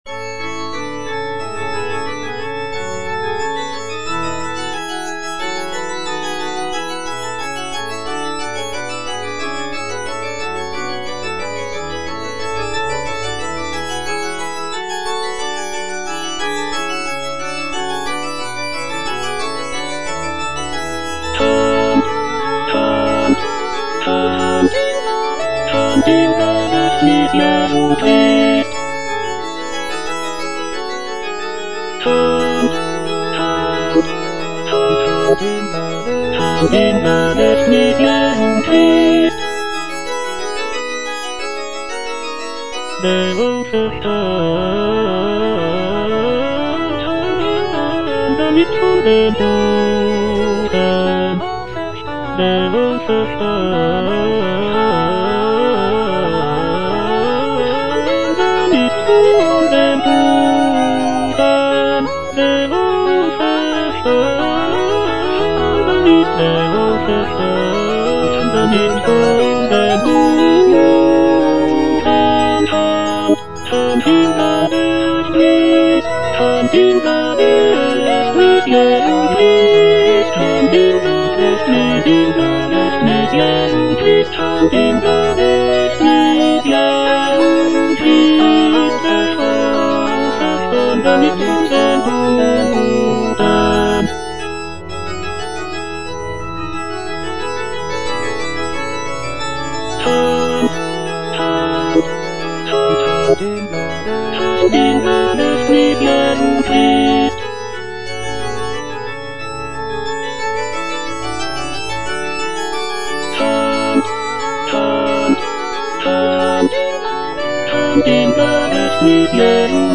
Cantata
Tenor (Emphasised voice and other voices) Ads stop